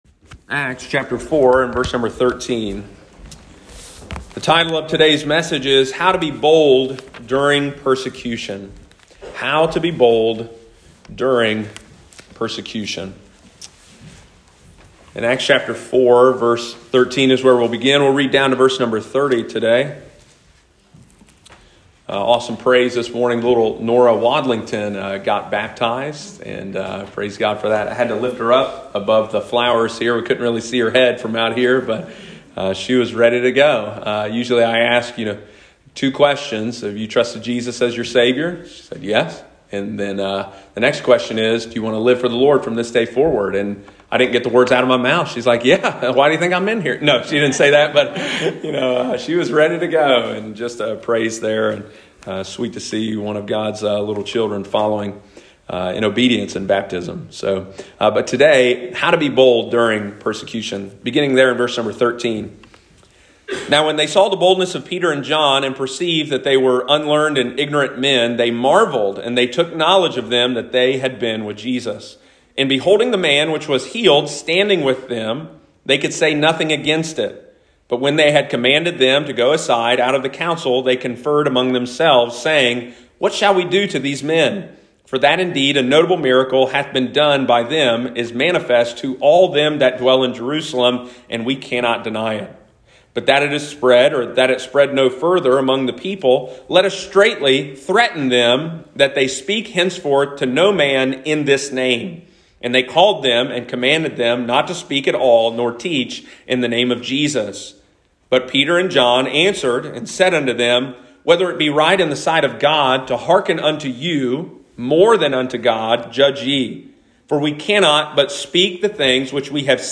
How to be Bold During Persecution – Lighthouse Baptist Church, Circleville Ohio